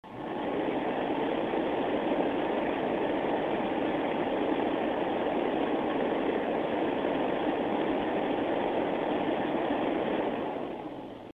FrostyTech Acoustic Sampling Chamber